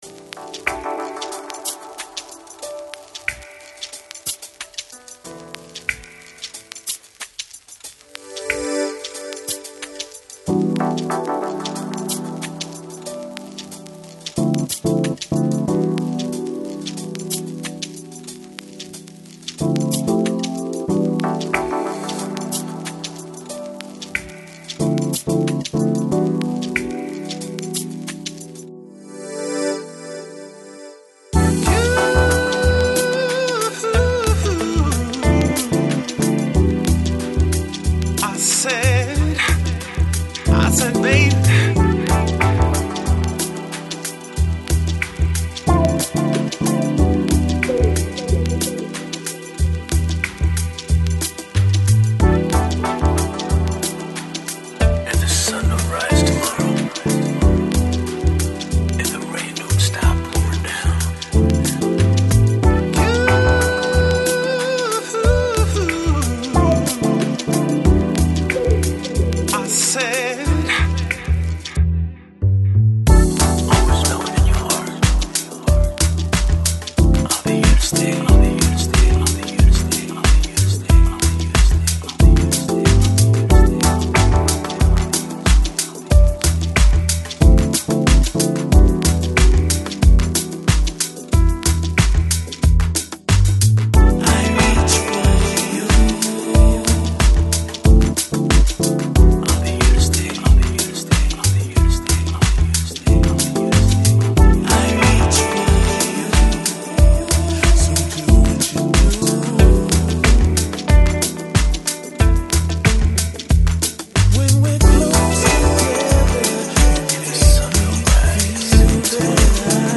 Жанр: Lounge, Chill Out, Downtempo, Soulful House